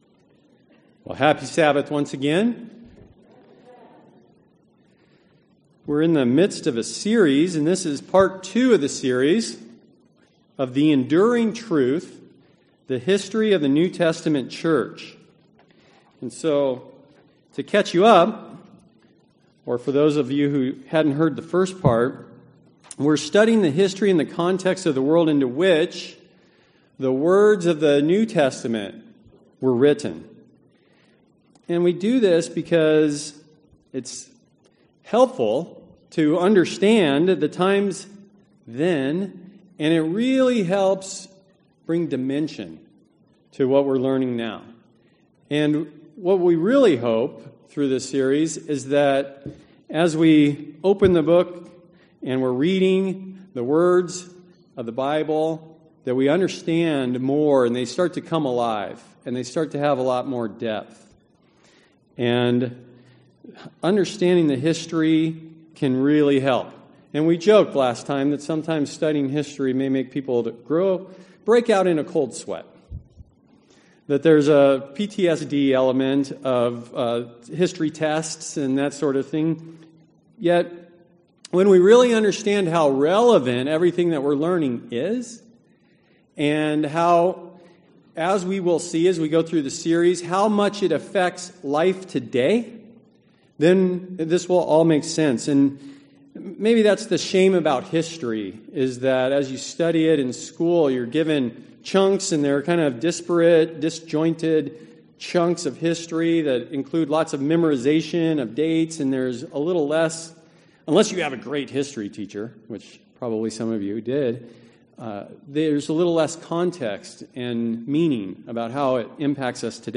Sermons
Given in Phoenix Northwest, AZ